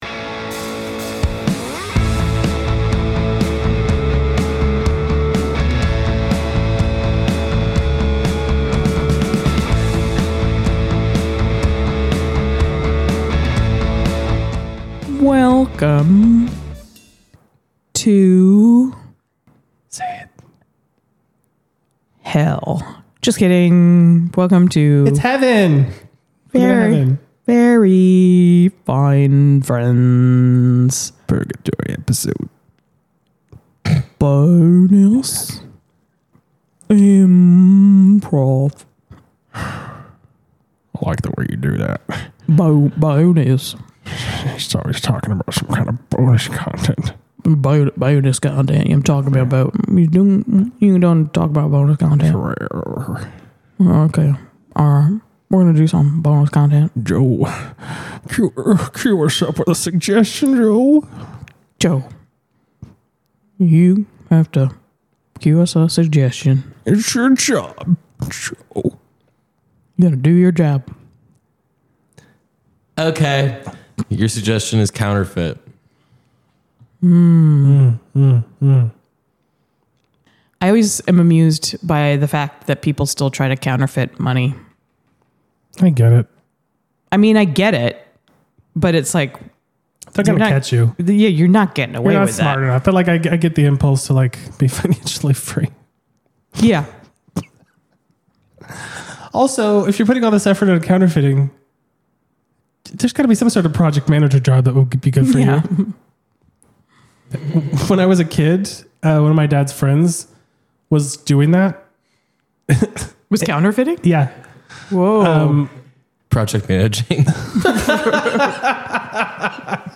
Bonus IMPROV - Why Are You Kissing Everything Back Here?